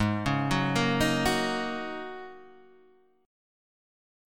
G#9sus4 chord {4 4 4 6 4 6} chord